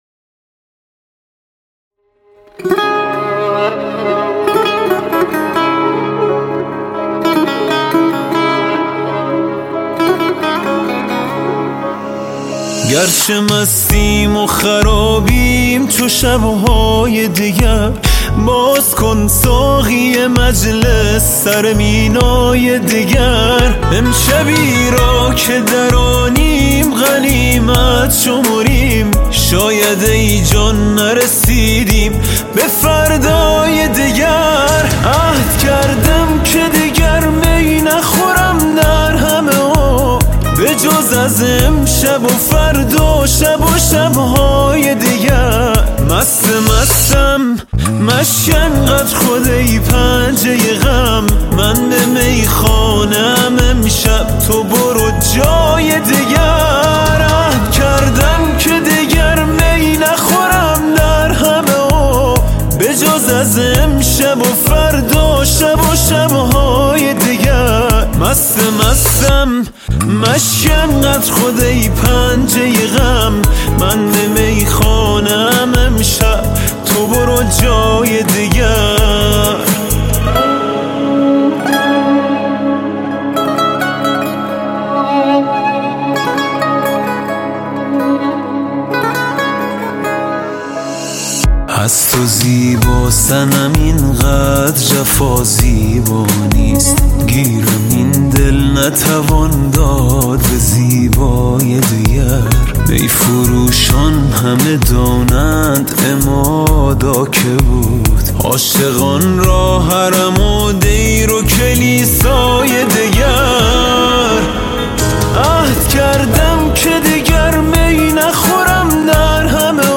پاپ عرفانی